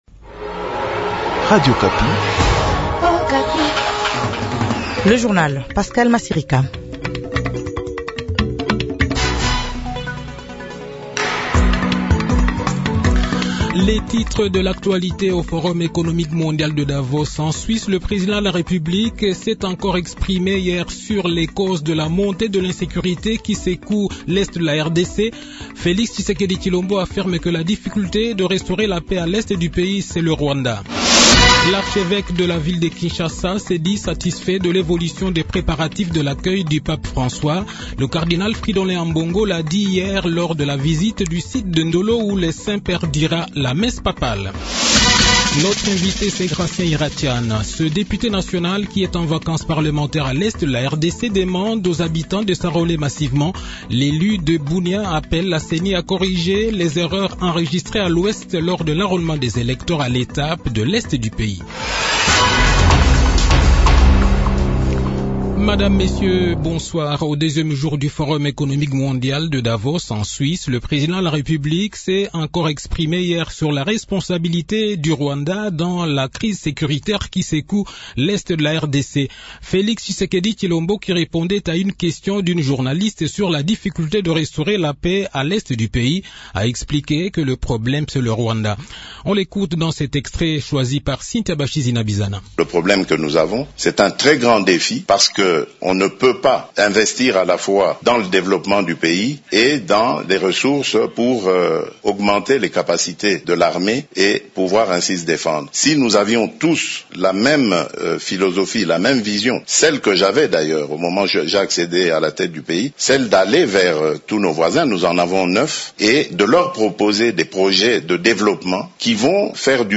Journal Soir
Le journal de 18 h, 18 janvier 2023